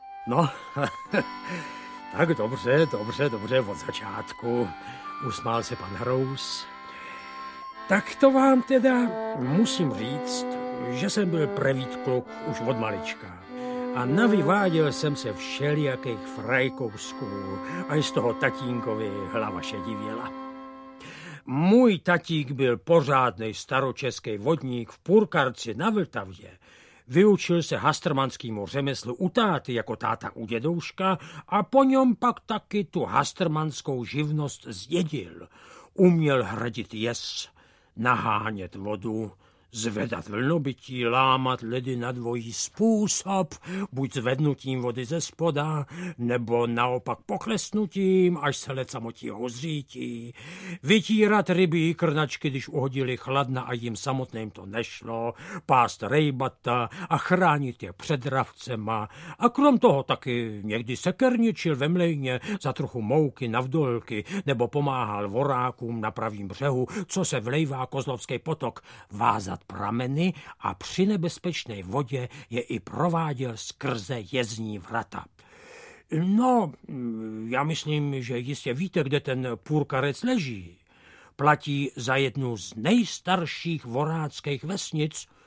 Hastrmani audiokniha
Ukázka z knihy